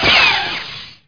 sound_ricochet03.wav